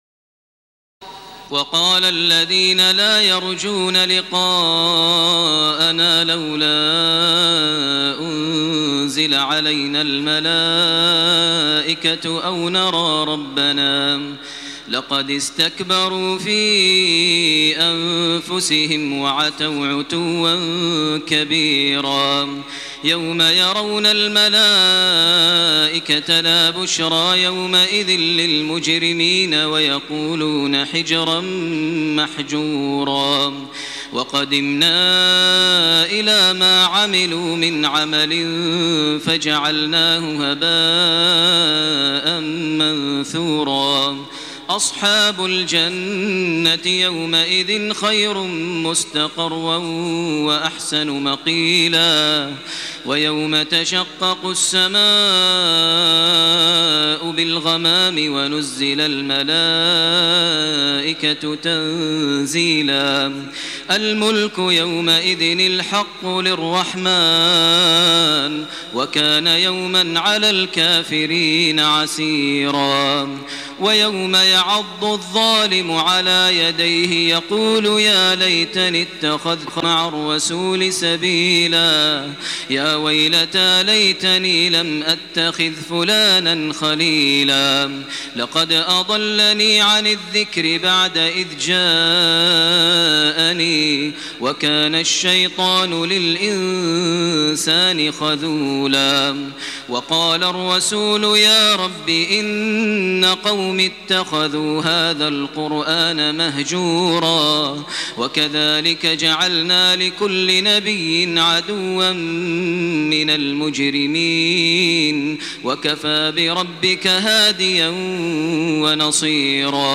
تراويح الليلة التاسعة عشر رمضان 1428هـ من سورتي الفرقان (21-77) و الشعراء (1-122) Taraweeh 19 st night Ramadan 1428H from Surah Al-Furqaan and Ash-Shu'araa > تراويح الحرم المكي عام 1428 🕋 > التراويح - تلاوات الحرمين